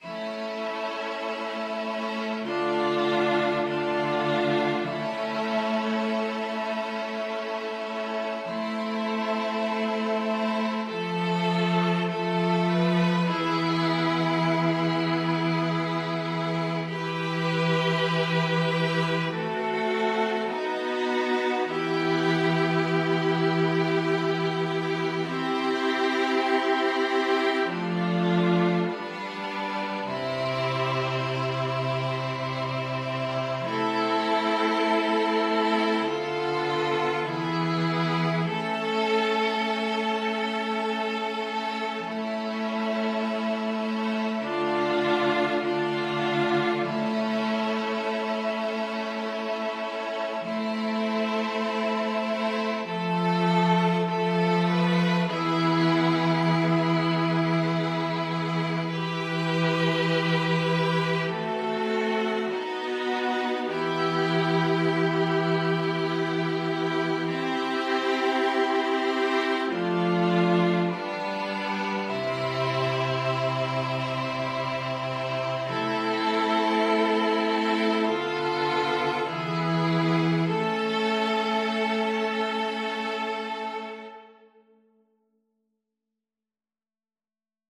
Free Sheet music for String Quartet
Violin 1Violin 2ViolaCello
E minor (Sounding Pitch) (View more E minor Music for String Quartet )
4/4 (View more 4/4 Music)
Classical (View more Classical String Quartet Music)
purcell_queen_STRQ.mp3